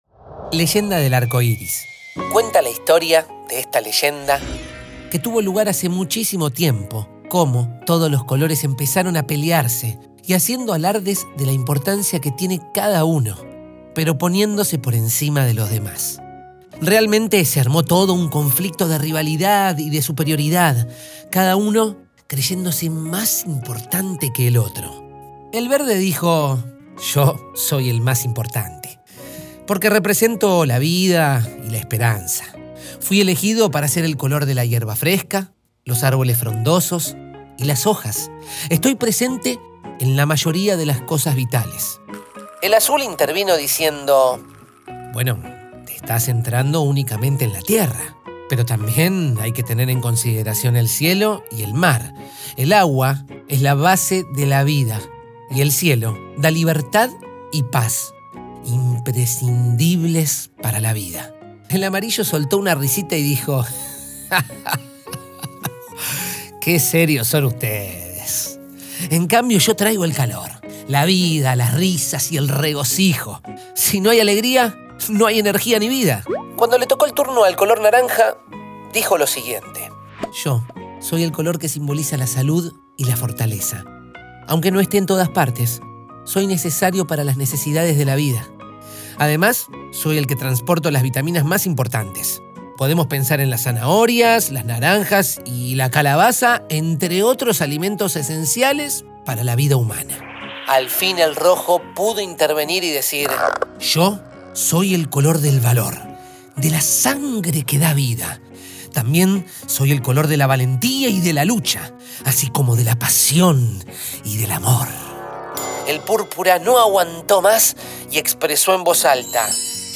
Compartir este cuento contado: